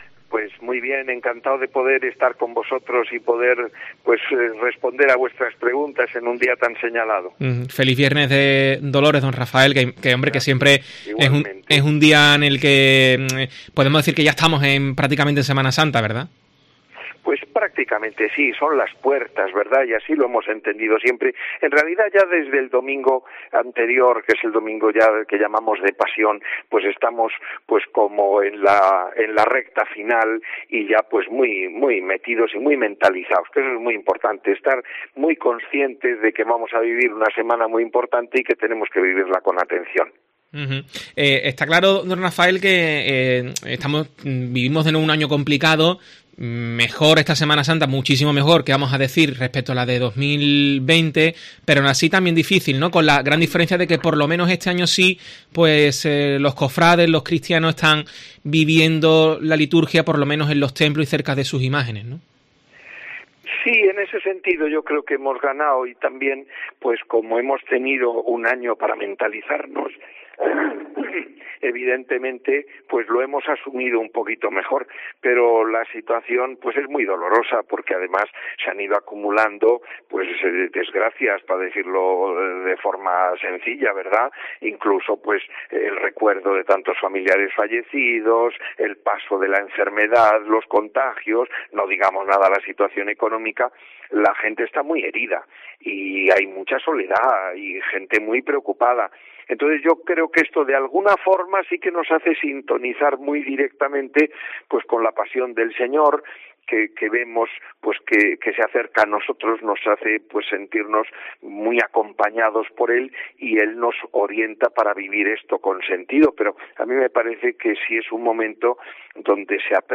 Monseñor Rafael Zornoza, obispo de la Diócesis de Cádiz y Ceuta, en los micrófonos de COPE